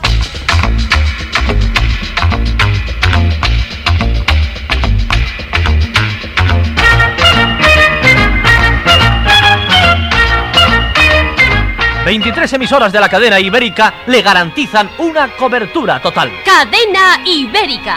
Indicatiu de la Cadena Ibérica